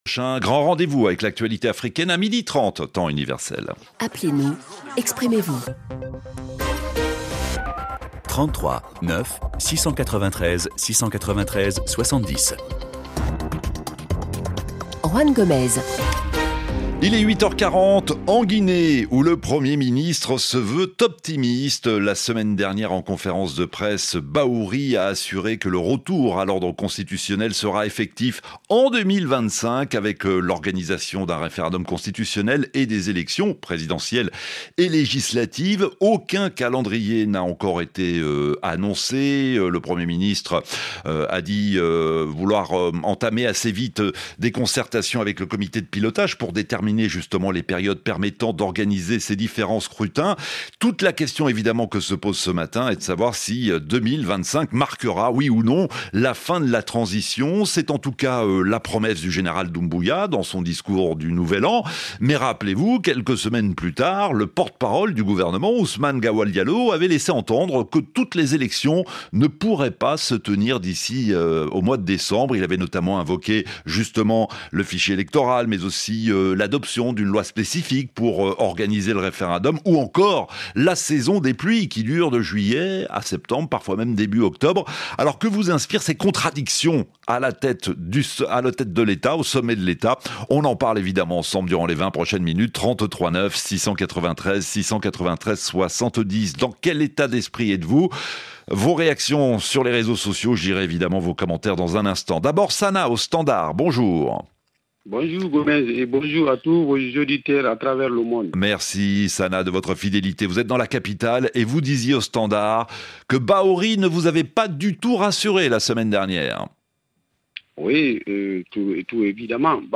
Le rendez-vous interactif des auditeurs de RFI.
L'émission est à la fois un lieu de décryptage de l'information grâce aux questions à la rédaction, mais aussi un lieu de débat où s'échangent idées et réflexions en provenance des cinq continents.